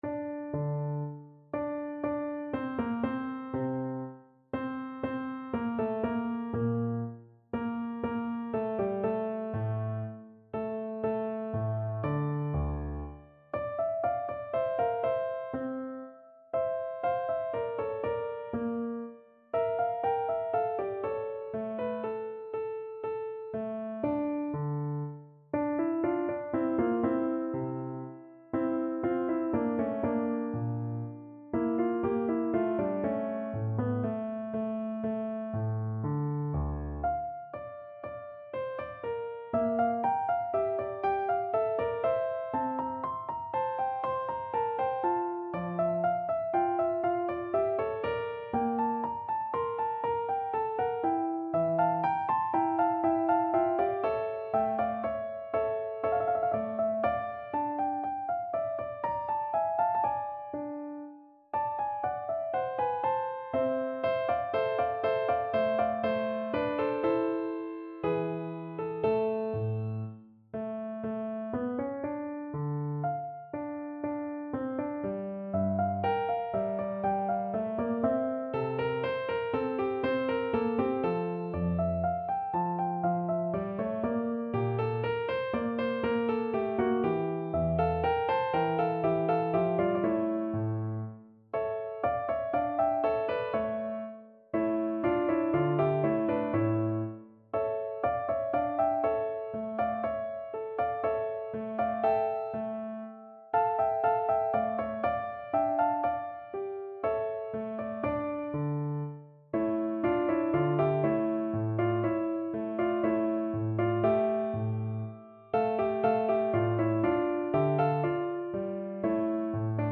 No parts available for this pieces as it is for solo piano.
3/4 (View more 3/4 Music)
Piano  (View more Advanced Piano Music)
Classical (View more Classical Piano Music)